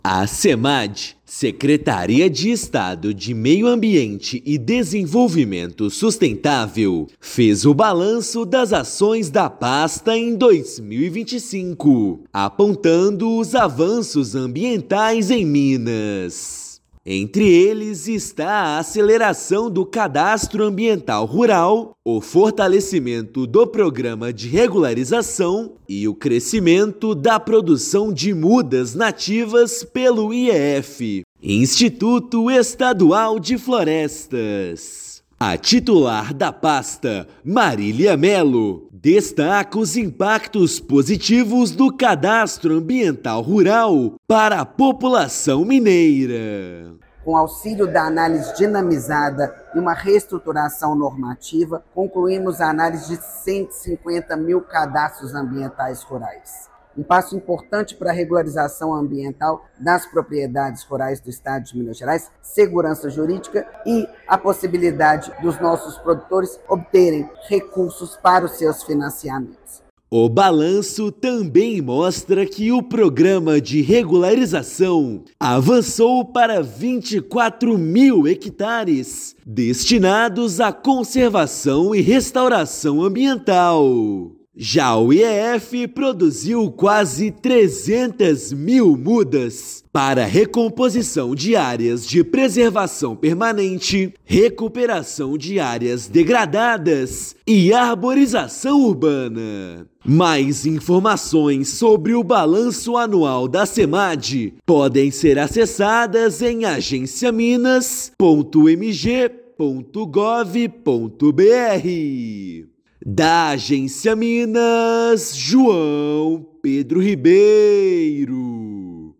[RÁDIO] Minas Gerais tem avanço histórico na regularização ambiental e produção de mudas nativas em 2025